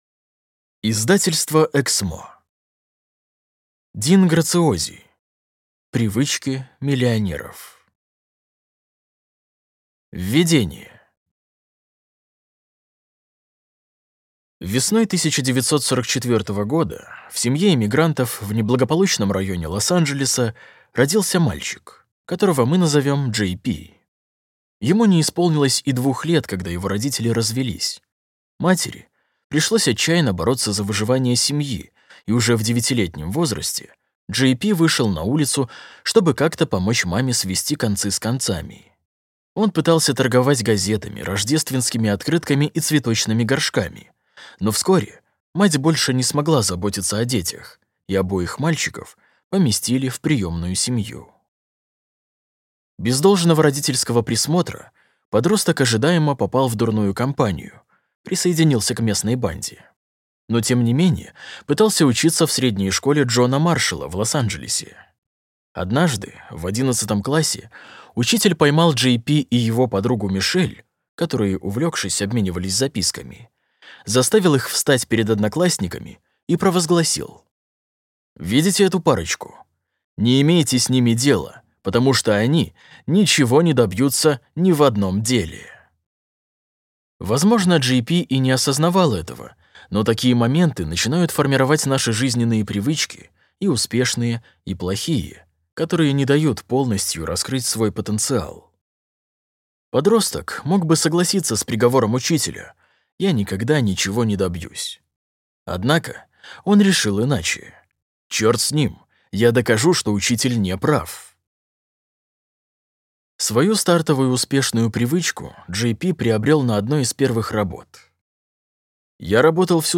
Аудиокнига Привычки миллионеров. Принципы денежного мышления | Библиотека аудиокниг